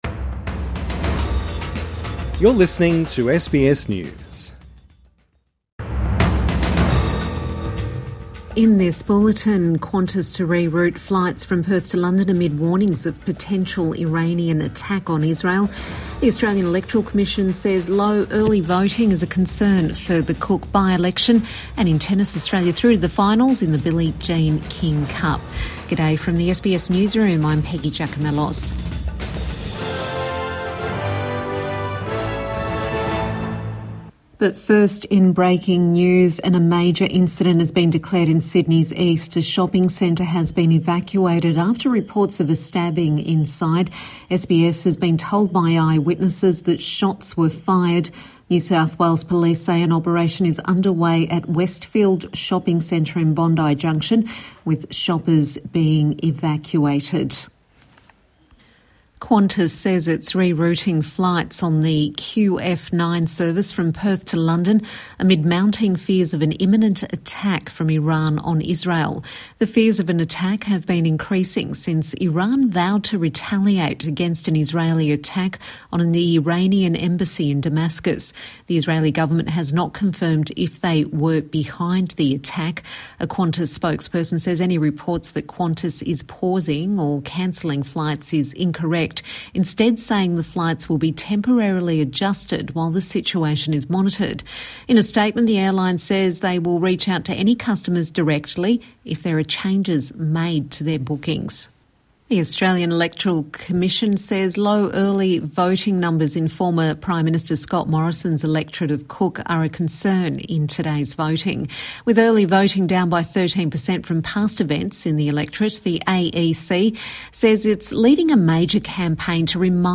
Evening News Bulletin 13 April 2024